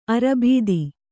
♪ arabīdi